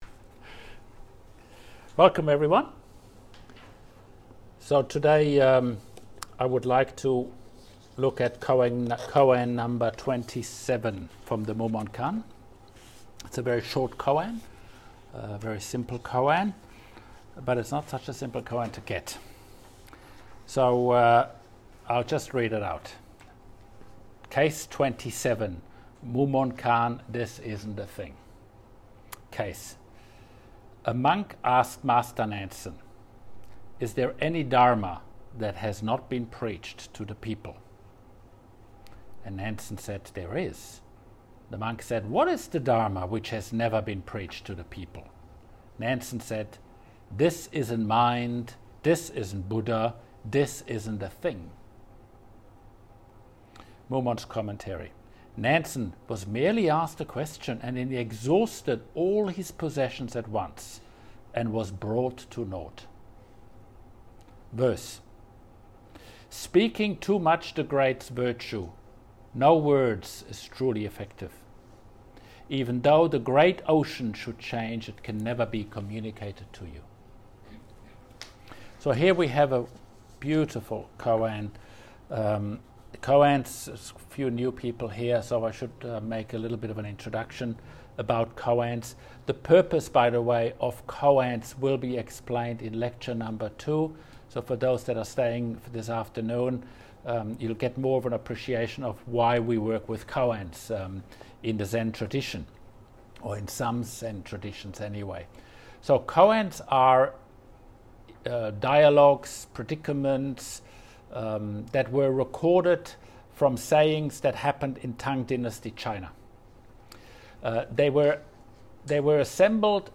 Teisho
at the Pathway Zen Zenkai at Spring Hill, QLD, Australia.